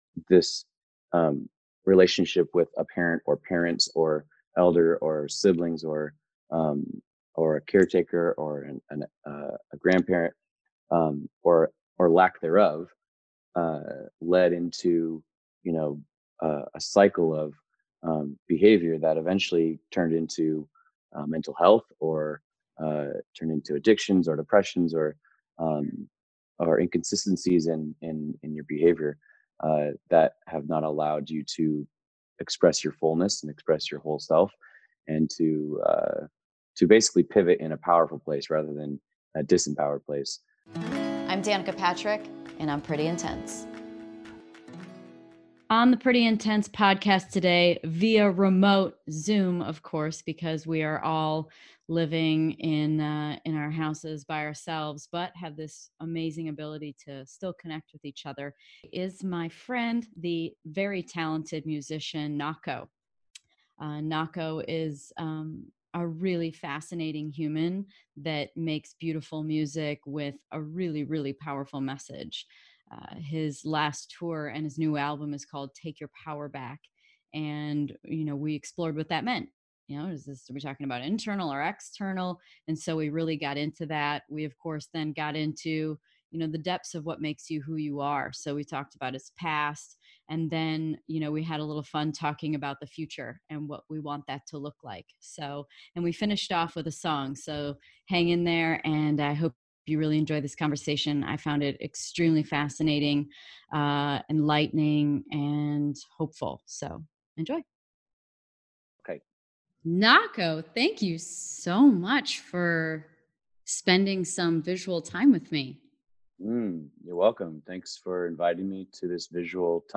Welcome to our second remote podcast.
We talk about his upbringing, his inner struggles, and his beautiful music. Nahko even sings us my favorite song.